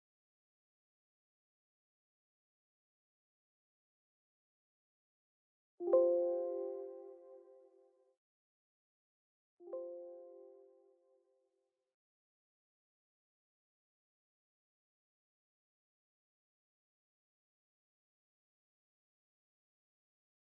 Ich höre aber nur ein Rattern eines Lüfters ! Über Kopfhörer hört man ganz fein ein Geräusch das unregelmäßig ist und sich an hört wie ein brummen...